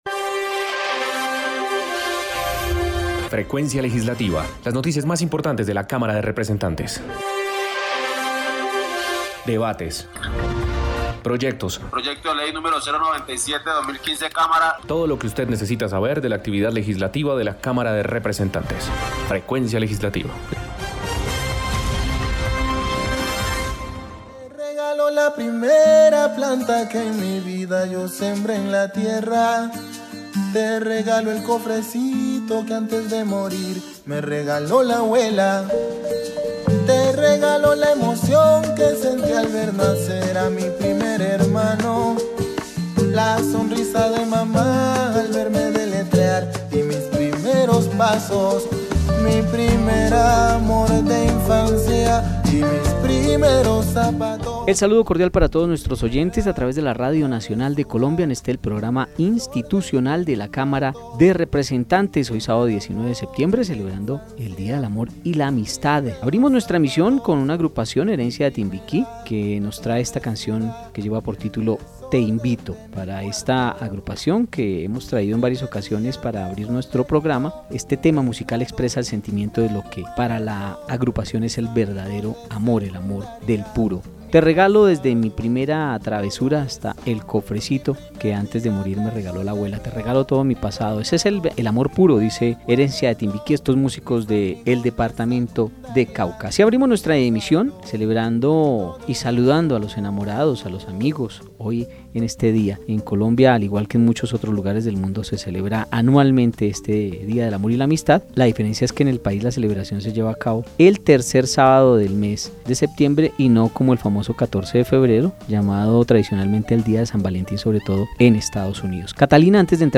Programa Radial Frecuencia Legislativa. Sábado 19 de Septiembre de 2020